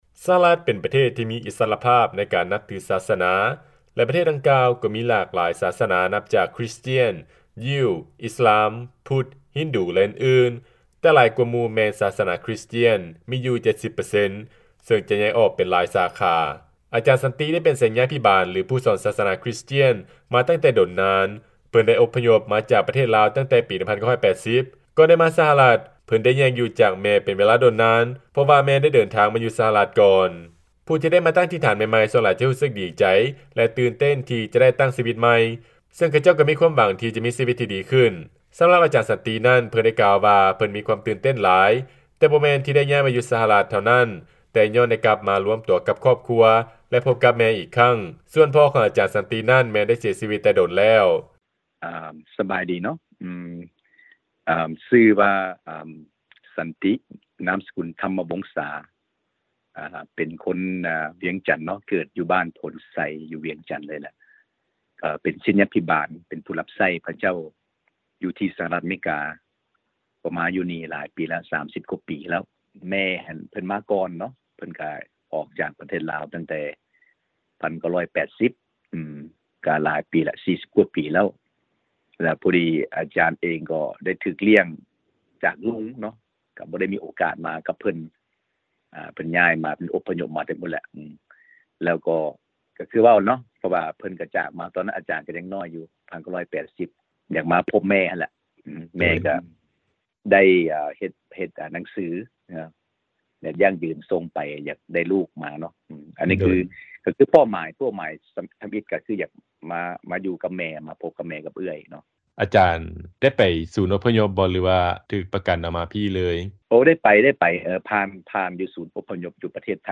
ຟັງລາຍງານ ວີໂອເອສຳພາດ